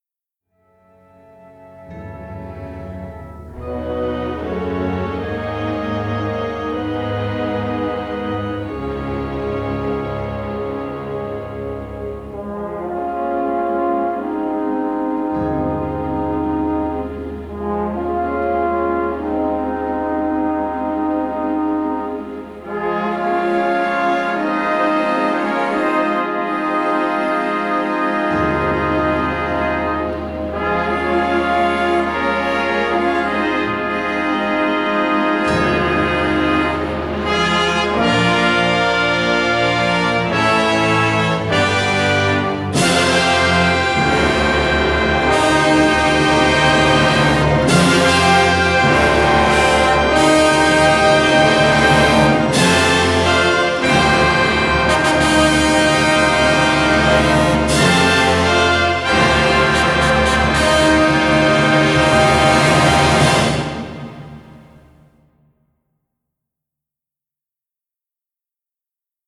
Score recorded at Shepperton Studios in England